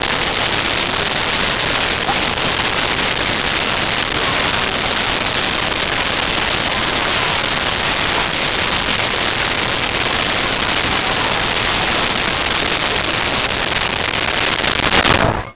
Outdoor Mall Crowd
Outdoor Mall Crowd.wav